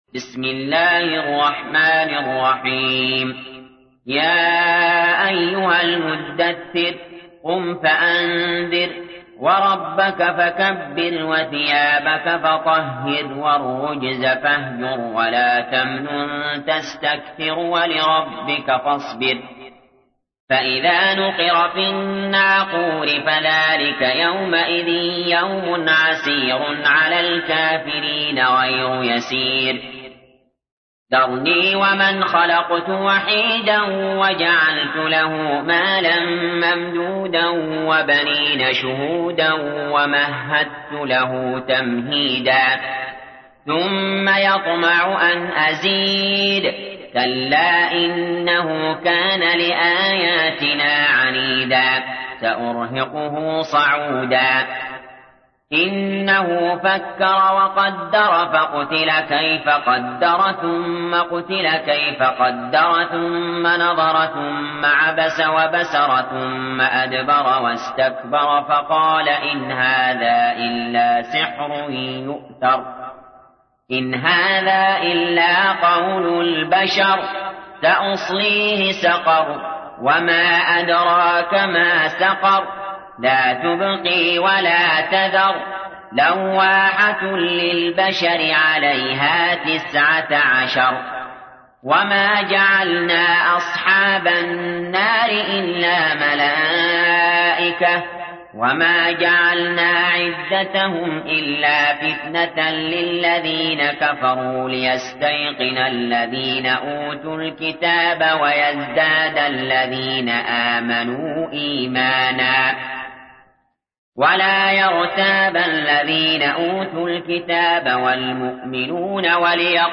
تحميل : 74. سورة المدثر / القارئ علي جابر / القرآن الكريم / موقع يا حسين